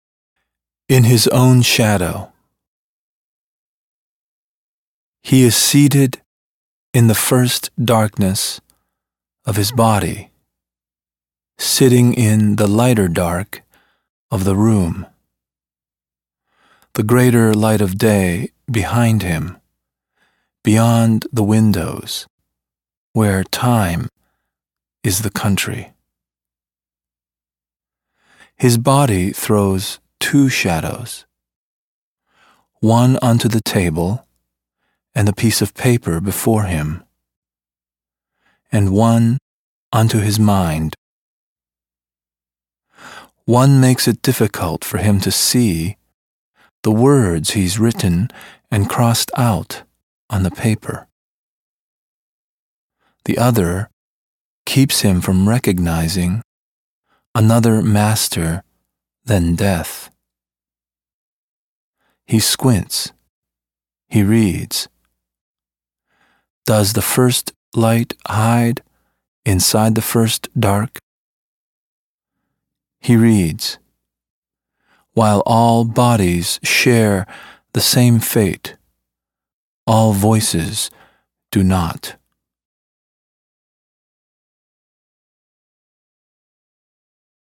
Li-Young Lee reads the first poem, "In His Own Shadow," from his fourth poetry collection, Behind My Eyes, published in January by Norton.